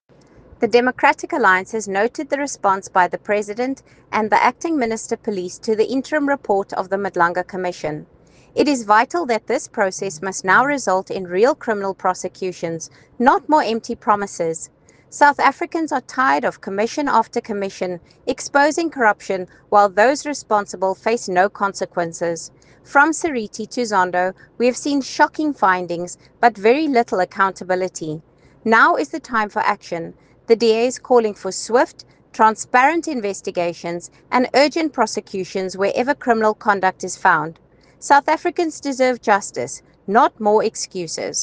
English soundbite by Lisa Schickerling MP